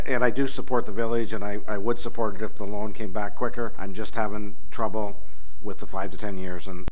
Council was set only to receive the request at Monday’s meeting, however, upon an amendment by Councillor Sean Kelly, agreed to enter into negotiations for a loan, including interest terms, that would be paid back over a period of 10 years.
Mayor Neil Ellis says he could support the loan if it was paid back as soon they raised the money through their fundraising campaign.